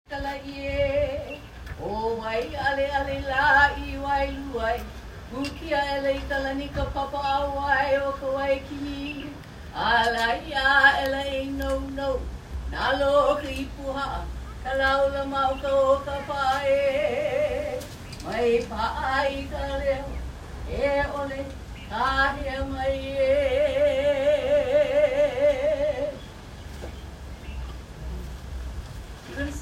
chanted the entire oli all the way through so that we could listen and record it (CLICK HERE for the video recording).